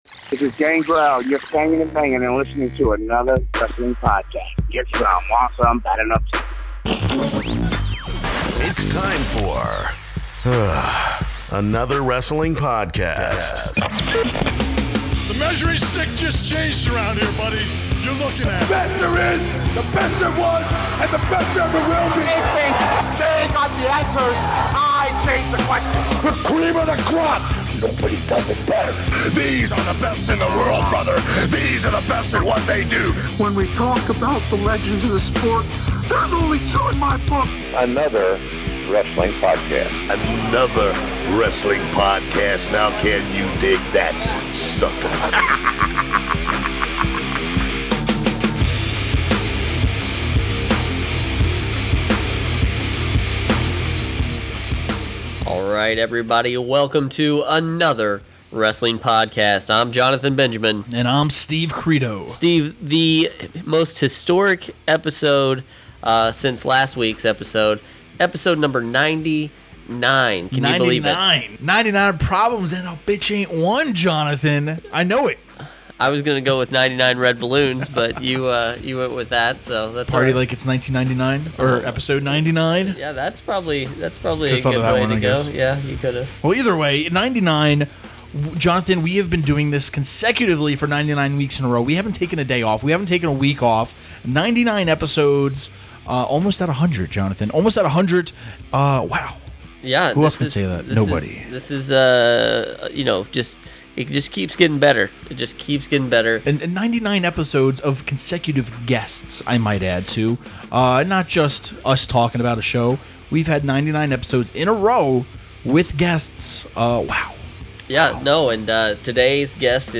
In this episode the guys dive into the supernatural, talking about some of the best mysterious and demonic gimmicks that have graced the squared circle. Joining them today is former WWE superstar Gangrel! He talks about how his vampire character came about, how the WWE Network has affected his popularity today, Edge & Christian as well as an incident with Donald Trump!